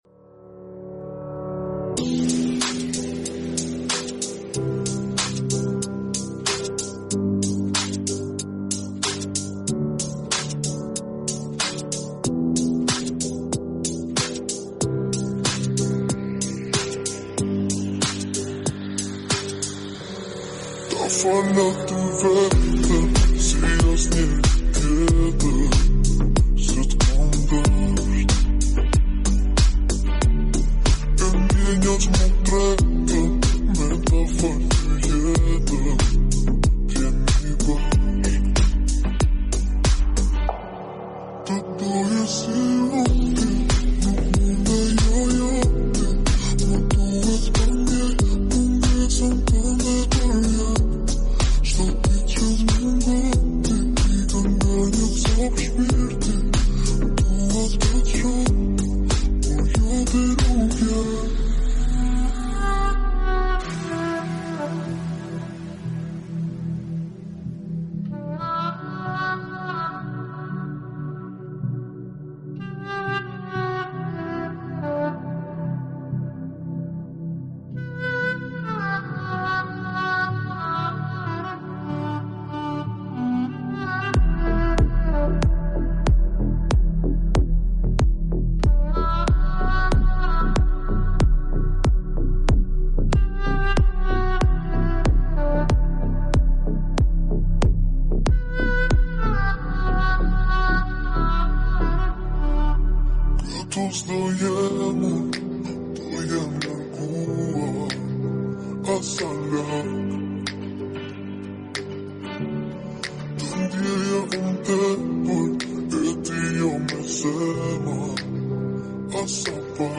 (Slowed + Reverd)